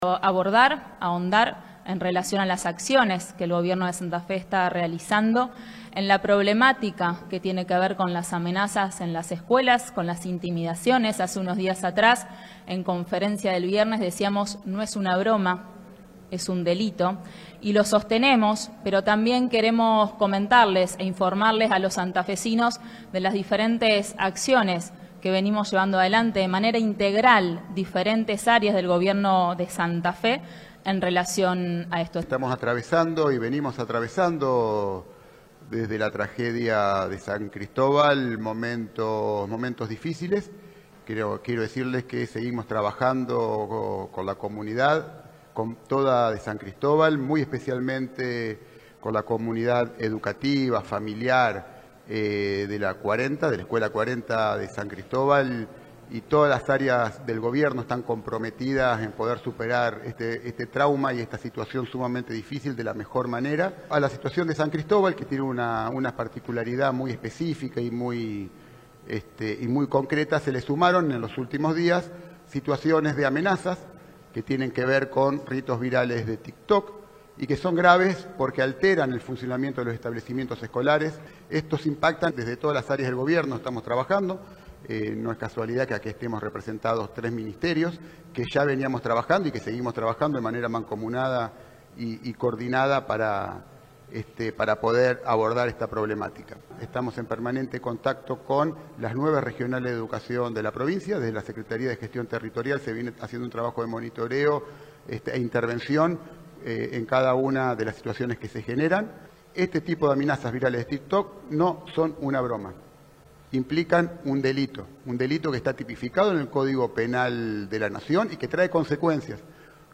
El detalle se brindó en una conferencia realizada en el Salón Auditorio de la Casa de Gobierno, encabezada por la vocera provincial, Virginia Coudannes, junto a los ministros de Educación, José Goity, y de Justicia y Seguridad, Pablo Cococcioni, además de la subsecretaria de Salud Mental, Liliana Olguín.
Declaraciones de Coudannes, Goity, Cococcioni y Olguín